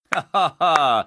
hahaa-clap